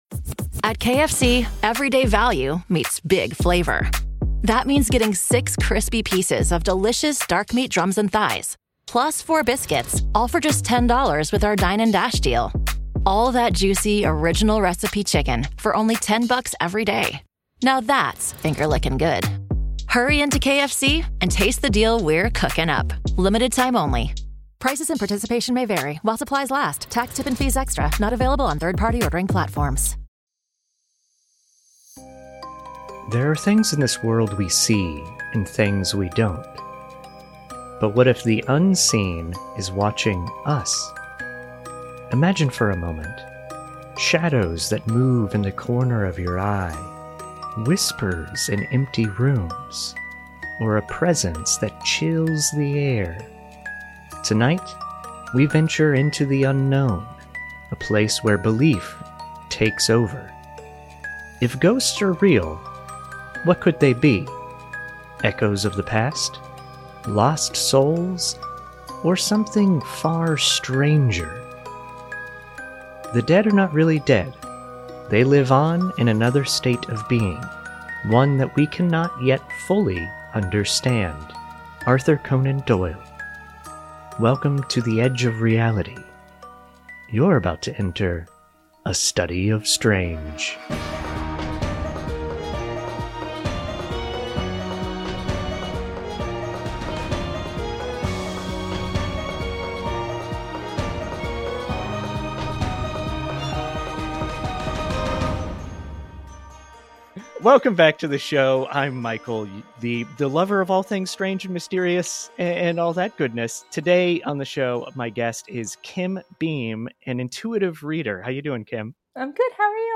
If ghosts are real, are we even close to understanding what they are? A discussion about spooky spirits